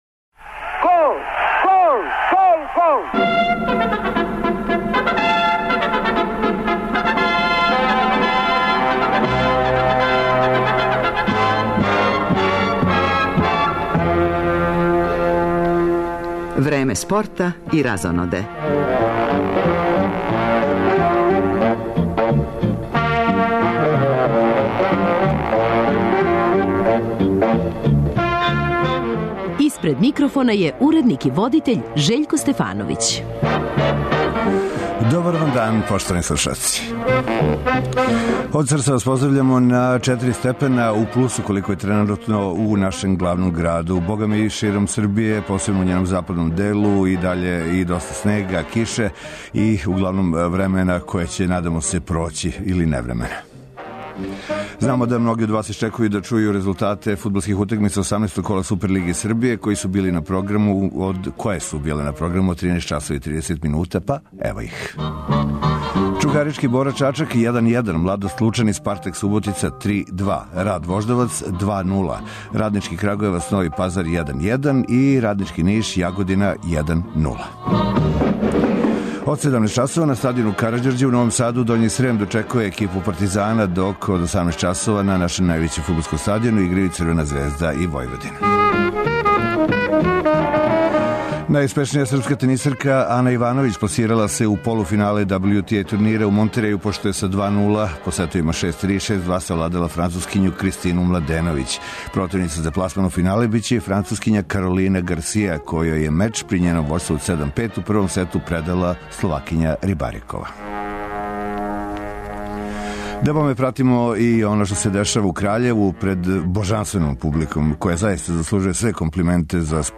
Током емисије пратимо и резултате утакмица Супер лиге Србије, као и важнијих европских фудбалских шампионата.